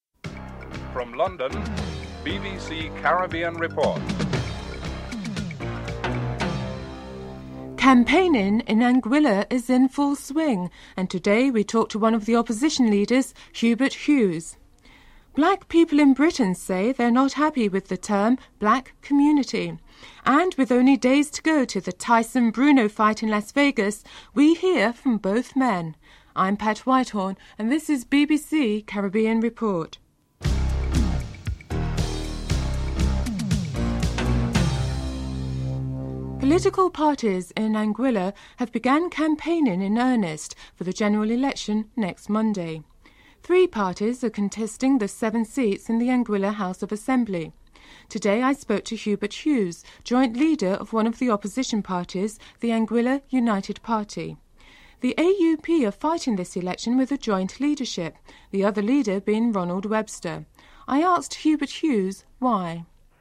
1. Headlines (00:00-00:35)
4. Financial News (06:11-07:57)